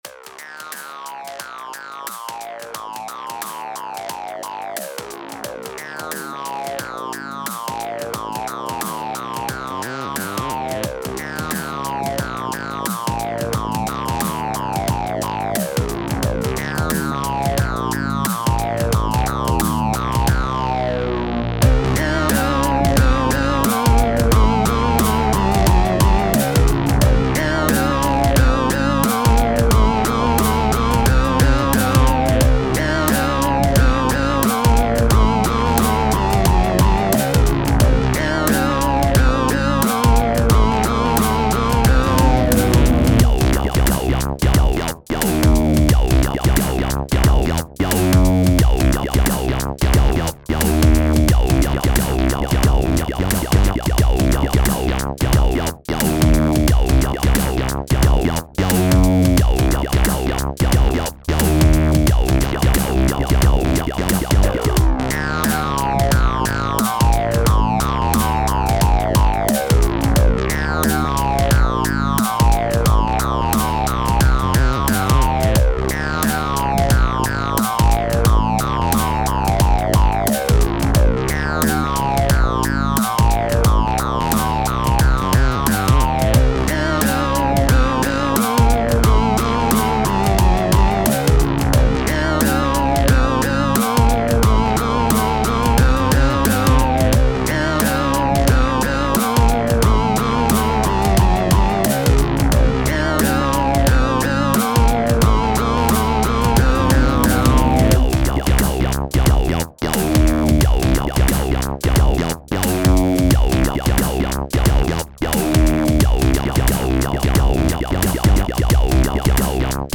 タグ: Beat コミカル ダラダラ 不気味/奇妙 変わり種 電子音楽 コメント: ダウナー系でルーズな雰囲気の楽曲。